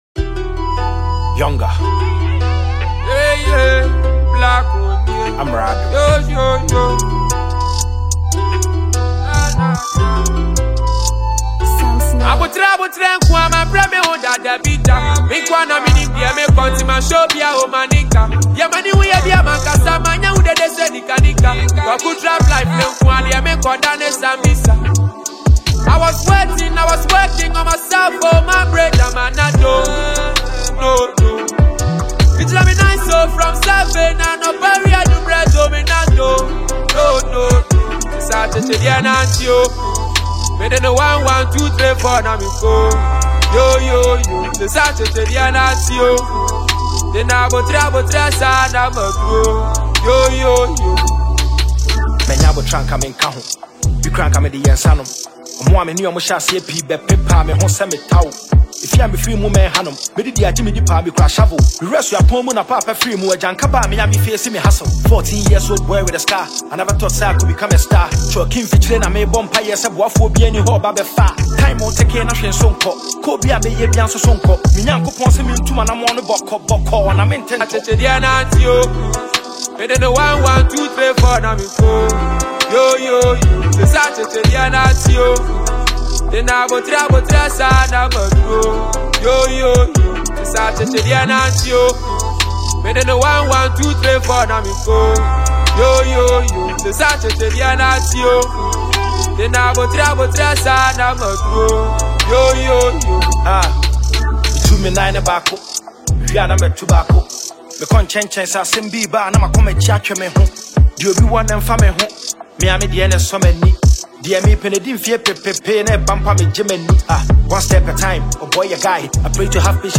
a sensational Ghanaian award-winning rapper